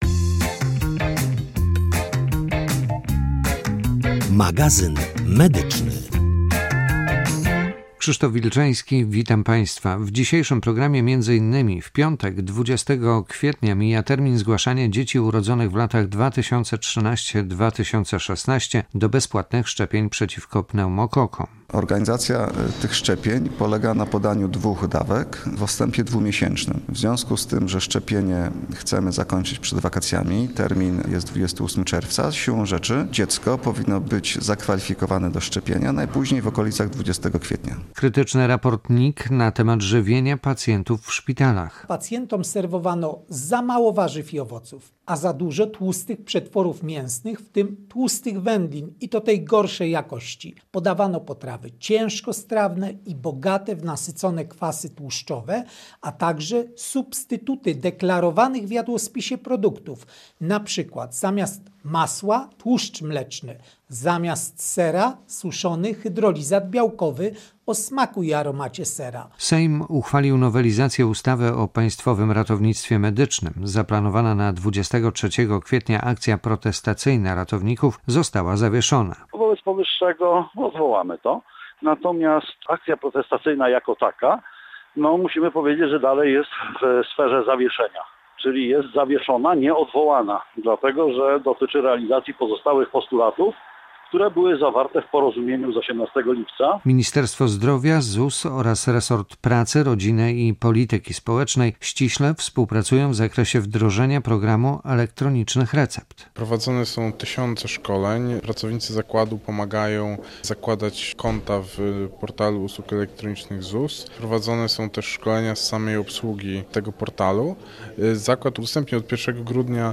Audycja w radiu PiK